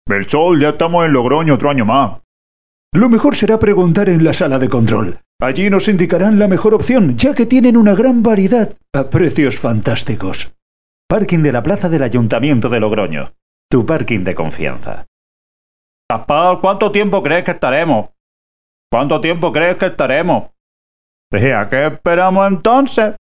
Spanish speaker, radio station voice, voice over, middle age voice.
Sprechprobe: Sonstiges (Muttersprache):